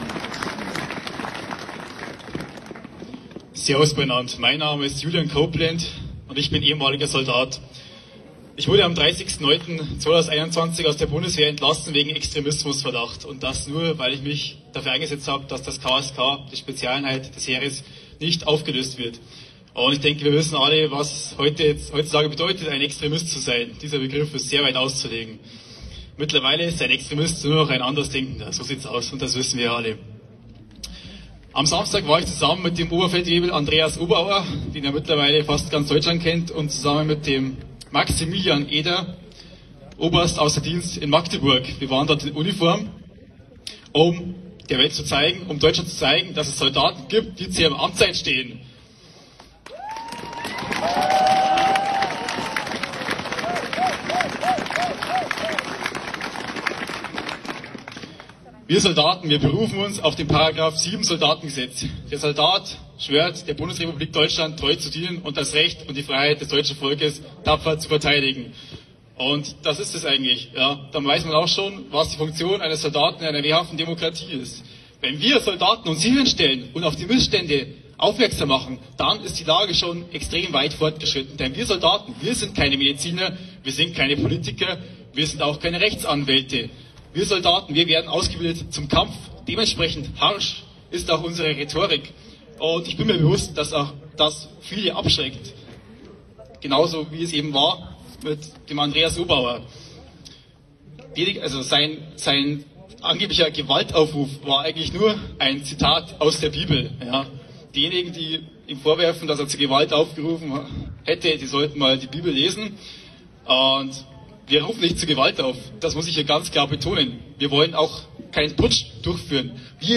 Hier seine Rede auf der Demo gegen die totalitären Diktatur.